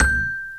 Xylophone C Major